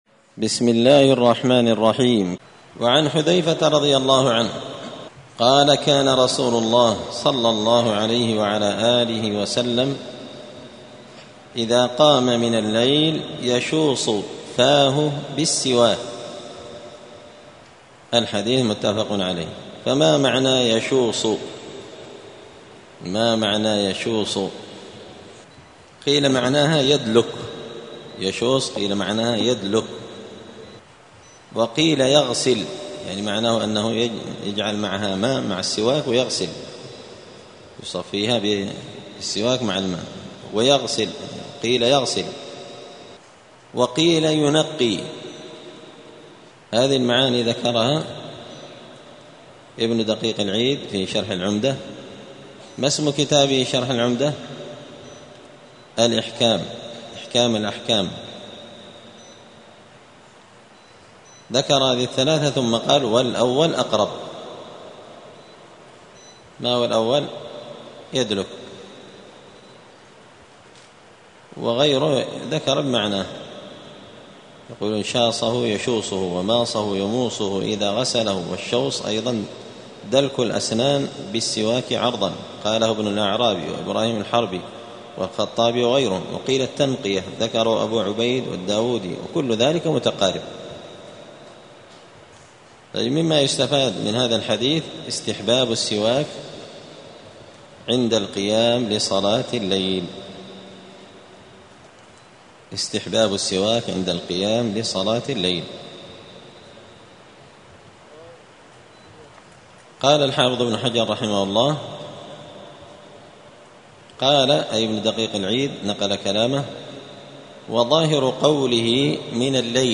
دار الحديث السلفية بمسجد الفرقان بقشن المهرة اليمن
*الدرس السابع عشر (17) {باب السواك مواضع استخدام السواك…}*